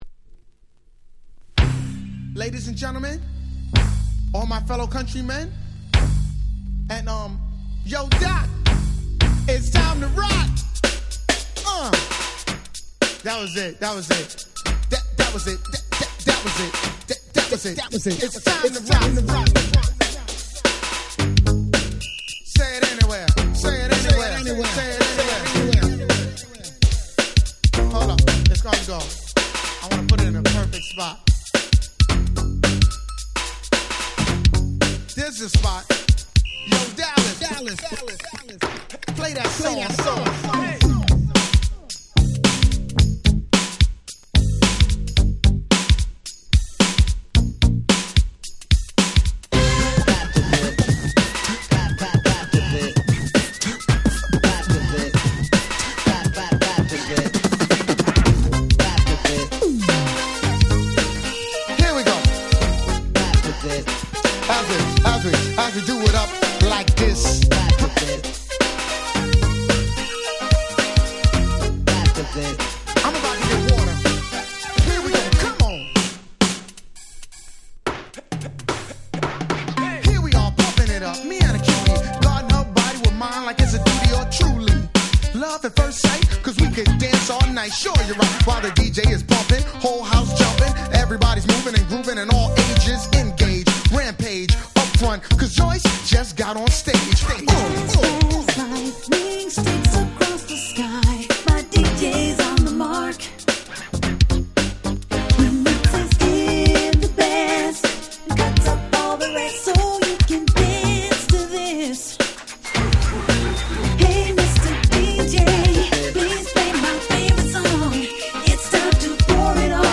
89' Very Nice New Jack Swing / 歌Rap / R&B !!
NJS New Jack Swing ハネ系 80's